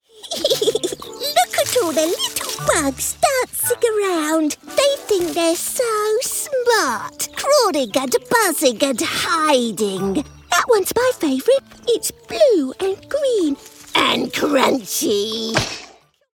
Her natural accent is neutral RP but she can also deliver convincing West Country, General Northern and Estuary accents.
standard british | natural
ANIMATION 🎬
child